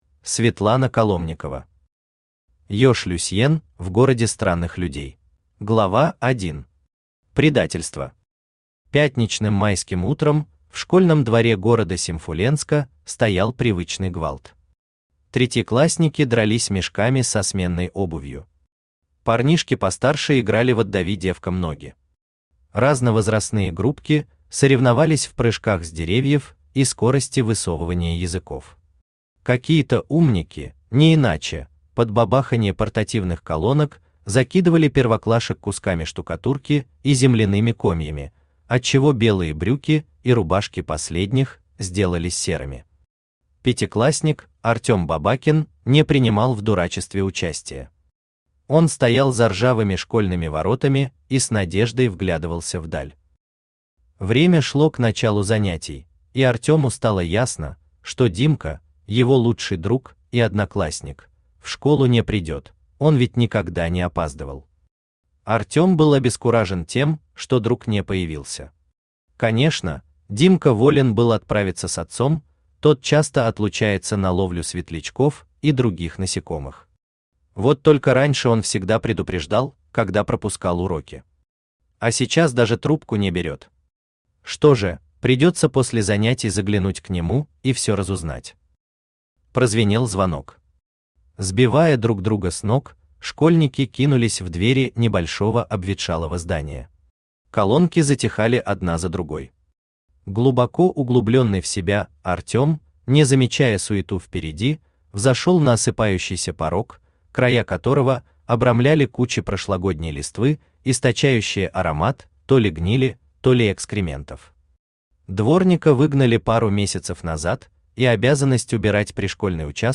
Аудиокнига Ёж Люсьен в городе странных людей | Библиотека аудиокниг
Aудиокнига Ёж Люсьен в городе странных людей Автор Светлана Игоревна Коломникова Читает аудиокнигу Авточтец ЛитРес.